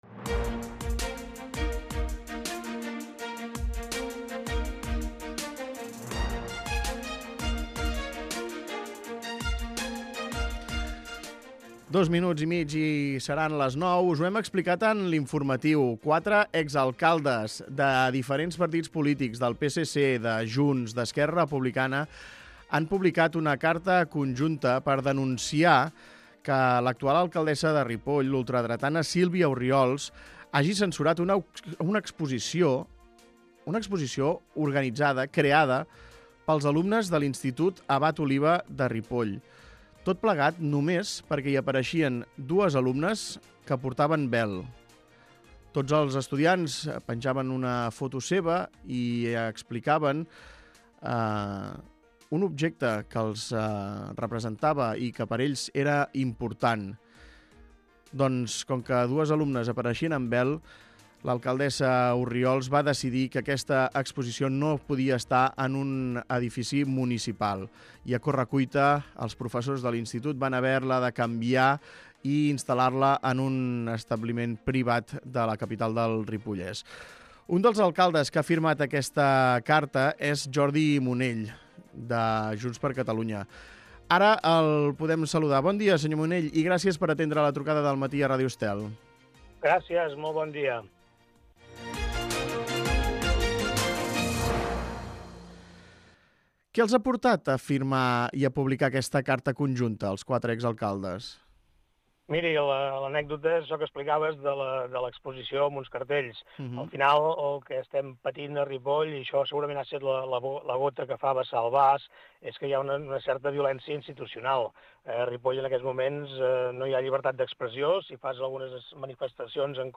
Entrevista a Joan Munell, exalcalde de Ripoll